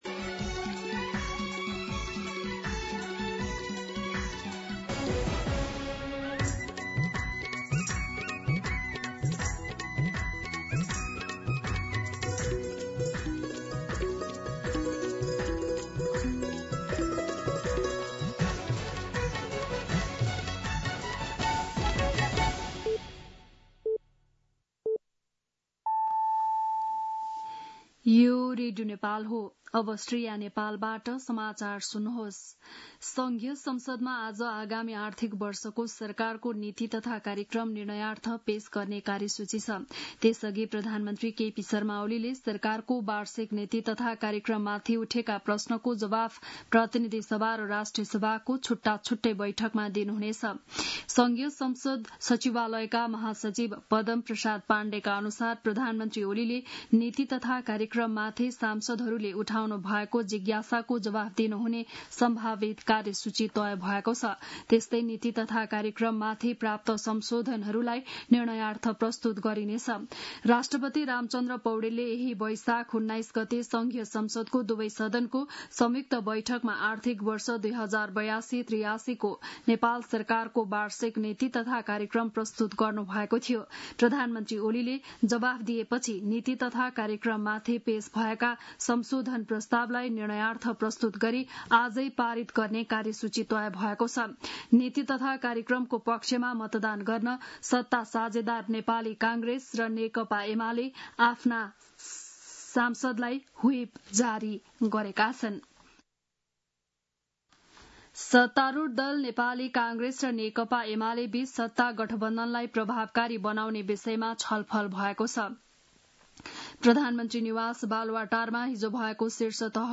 बिहान ११ बजेको नेपाली समाचार : २८ वैशाख , २०८२
11-am-Nepali-News-3.mp3